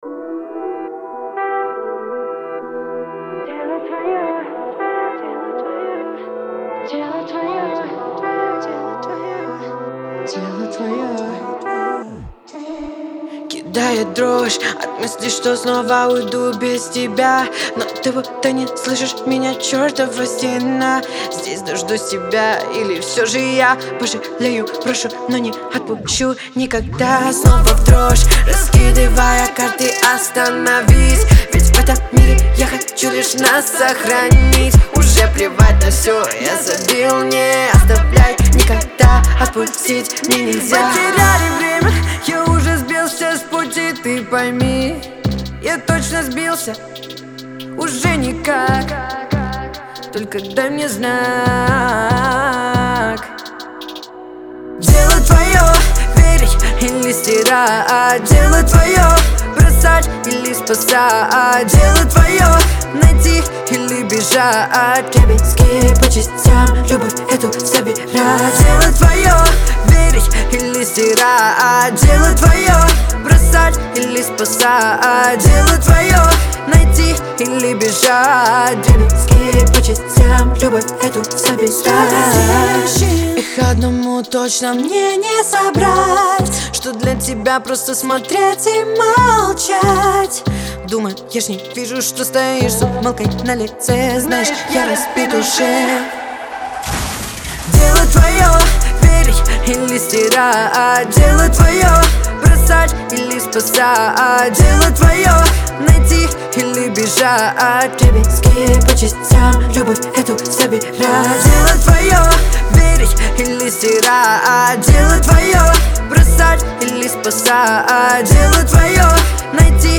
это яркий пример современного русского хип-хопа
уверенный и харизматичный вокал